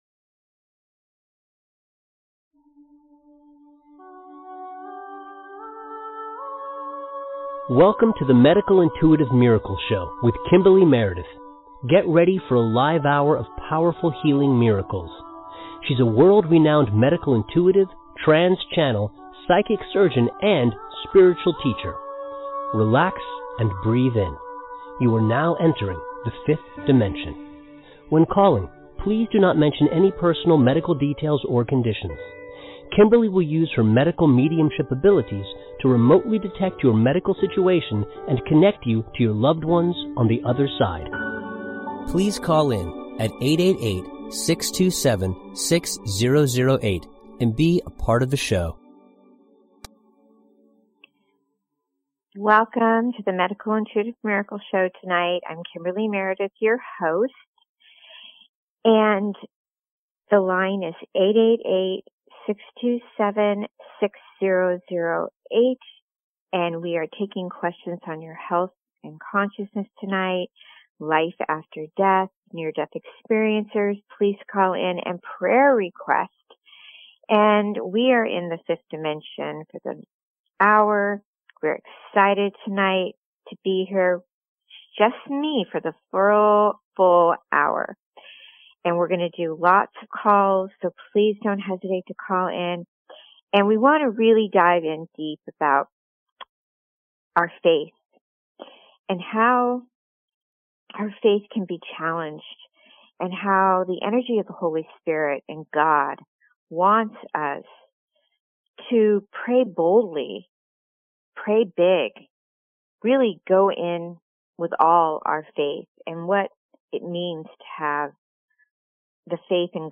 On air Live Intuitive Readings